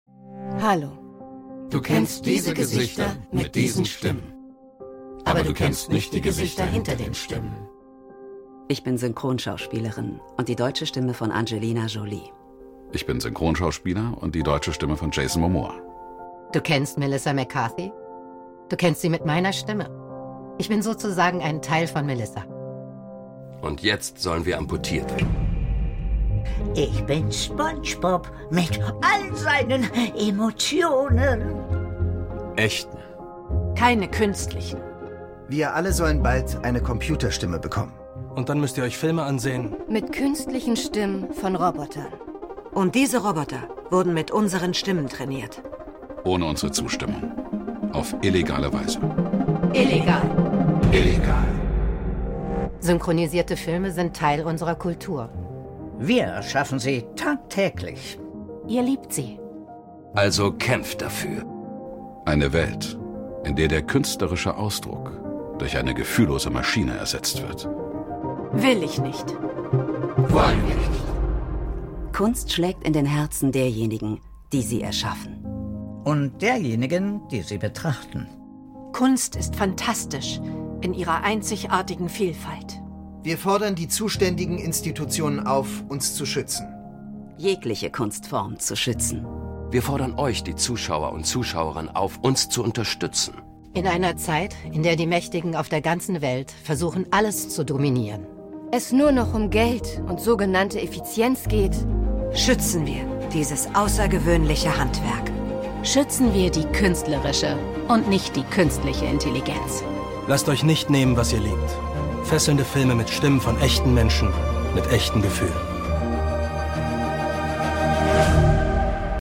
Synchronsprecherinnen & Synchronsprecher gegen KI sound effects free download